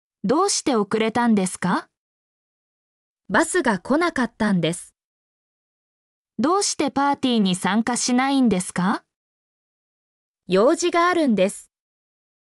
mp3-output-ttsfreedotcom-90_FwyIofvM.mp3